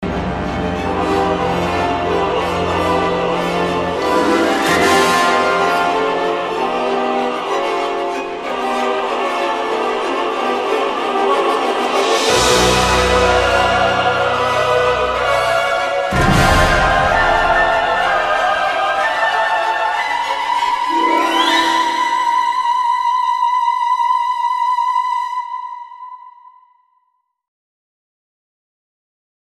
scream_c7t56.mp3